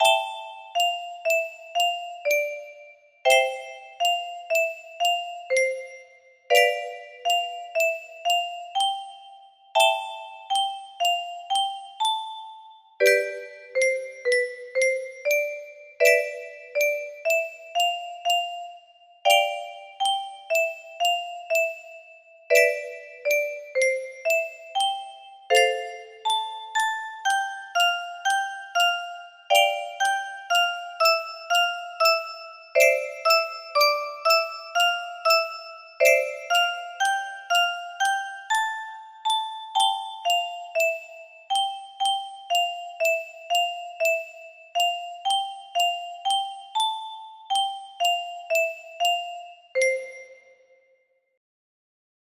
Unparalled Regality music box melody
Full range 60
Random Melody that sounds way too fancy for its own good.